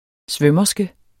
Udtale [ ˈsvœmʌsgə ]